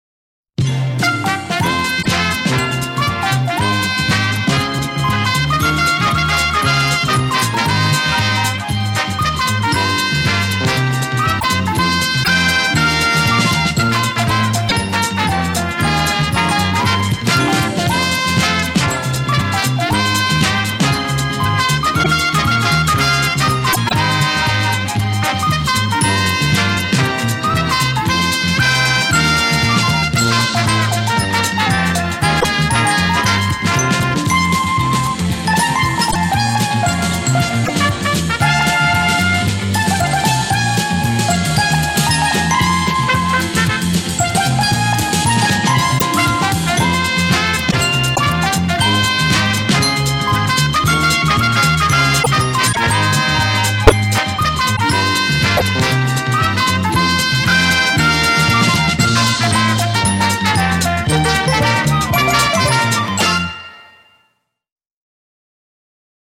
An early instrumental demo version